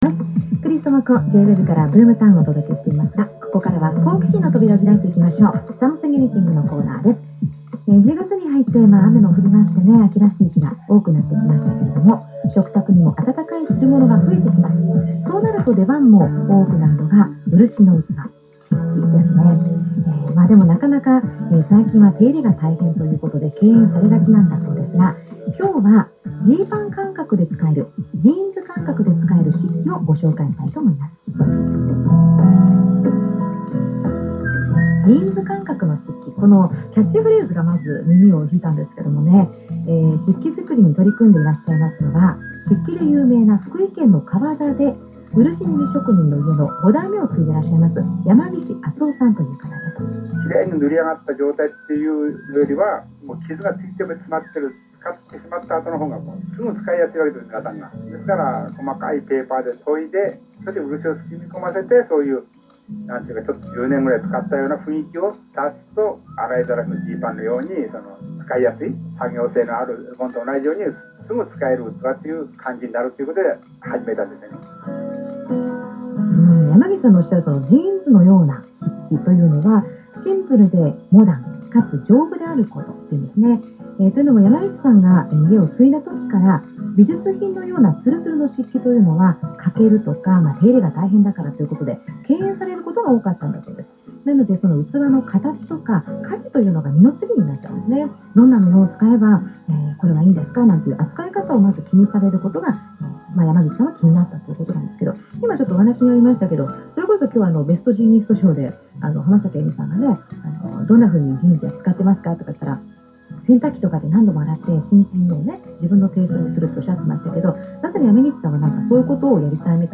●ジーパン感覚の漆器についてラジオで話しした（j-wave）